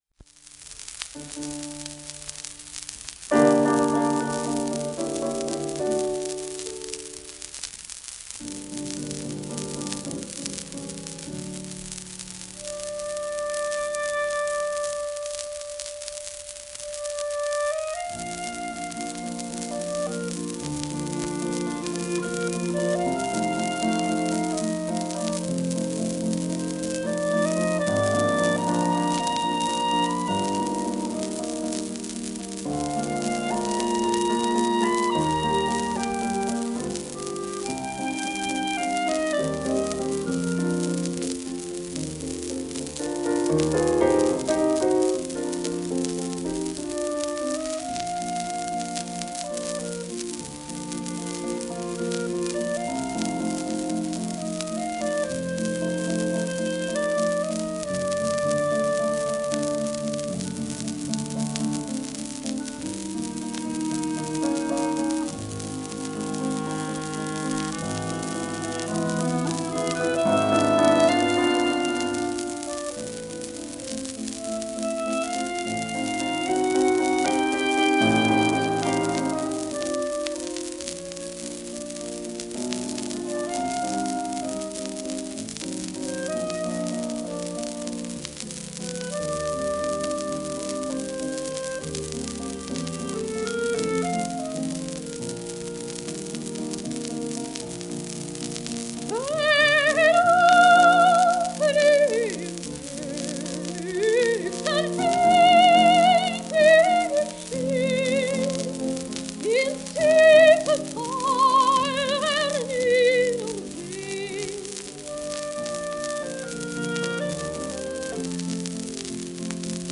盤質A-